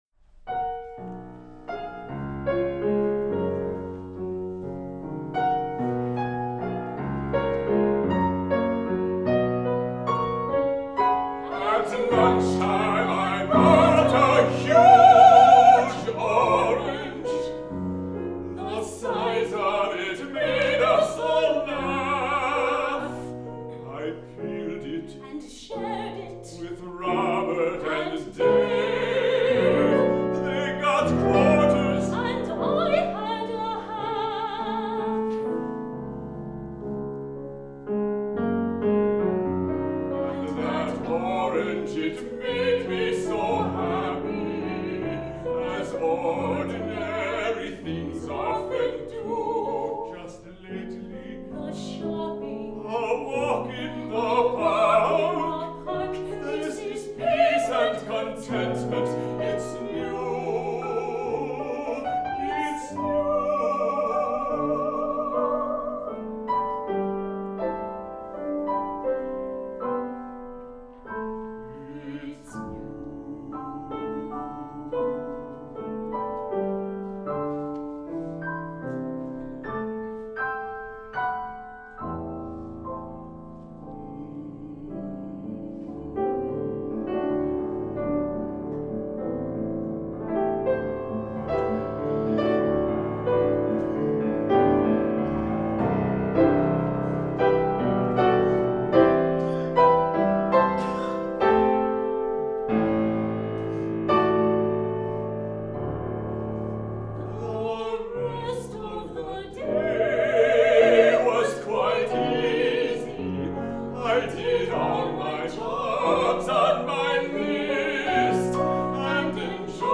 Duo song cycle for soprano, baritone and piano